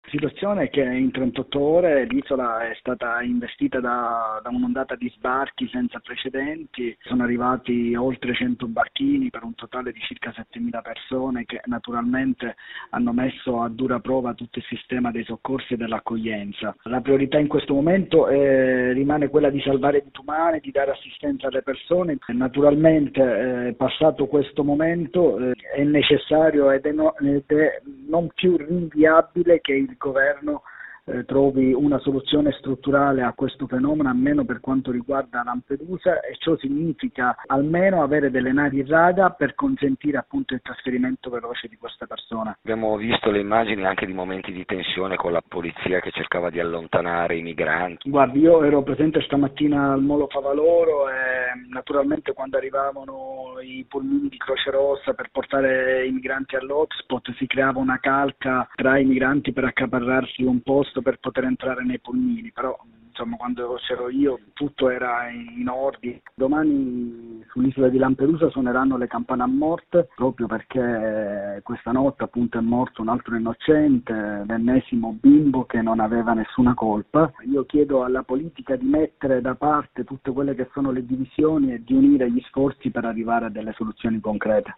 Nel pomeriggio abbiamo raggiunto il sindaco di Lampedusa Filippo Mannino:
Mannino-19-30-sindaco-Lampedusa.mp3